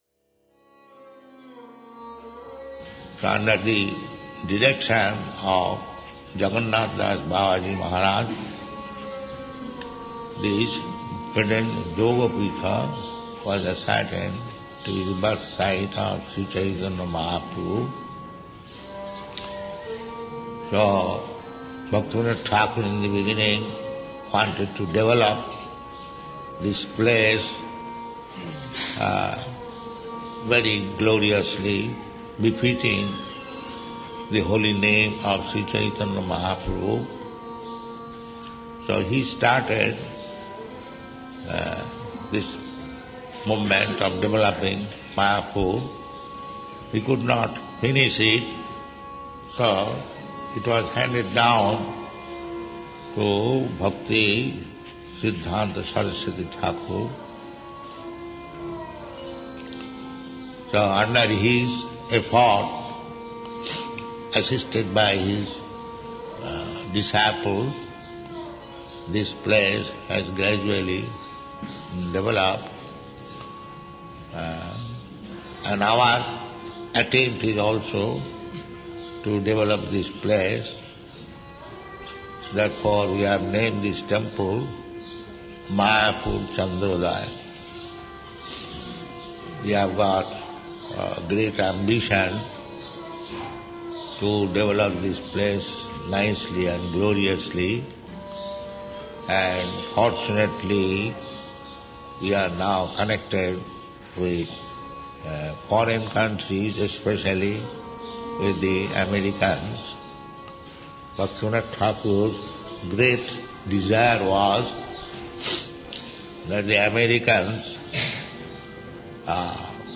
(760221 - Lecture Festival Appearance Day, Bhaktisiddhanta Sarasvati - Mayapur)